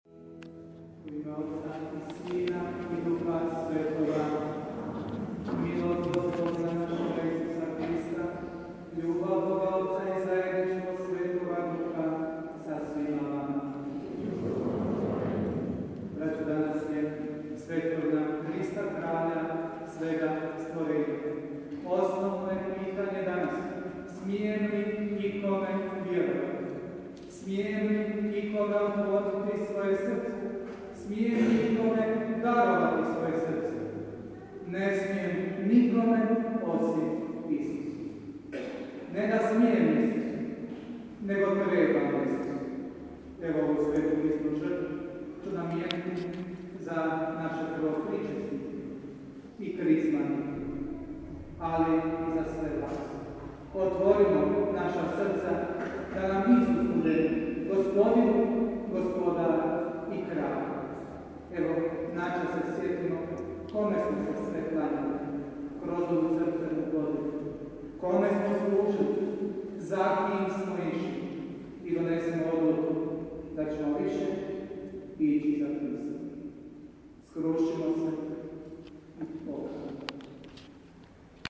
POČETAK sv. MISE
UVOD u misno slavlje – KRIST KRALJ 2015